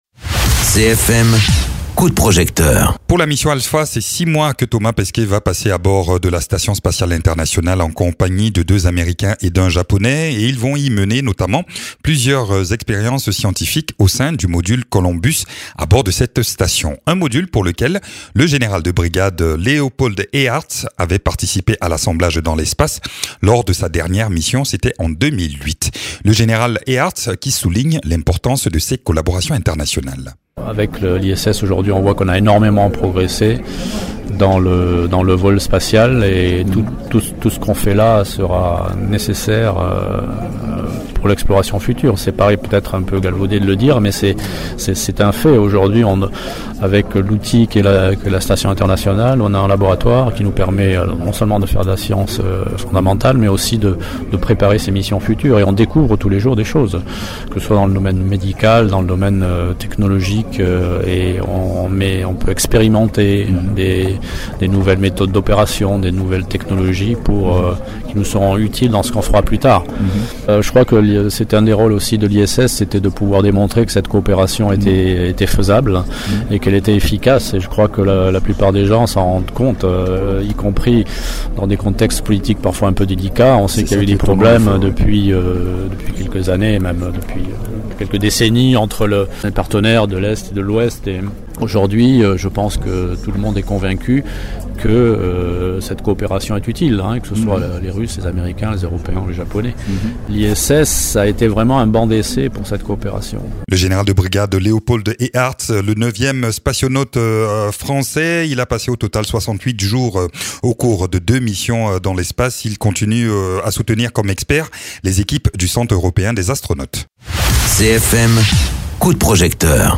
Interviews
Invité(s) : Léopold Eyharts, général de brigade de l’armée de l’air et spationaute.